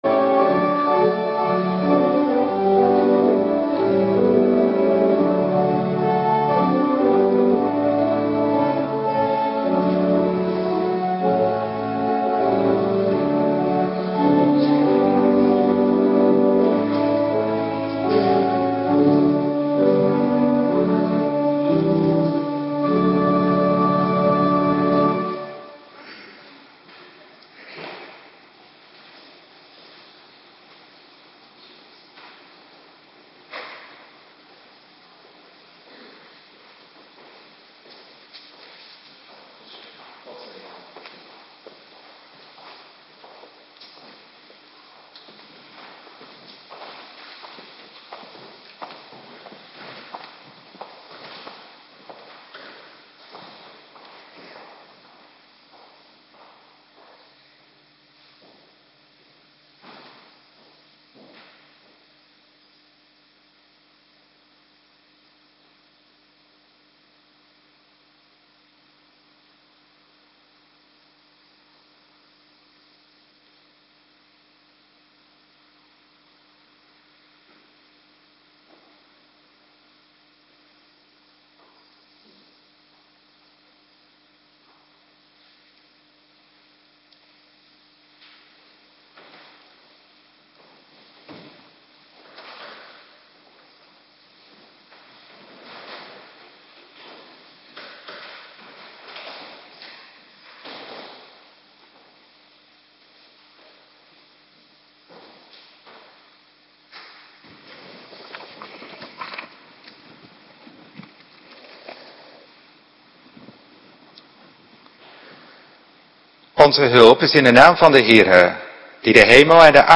Morgendienst Tweede Pinksterdag
Locatie: Hervormde Gemeente Waarder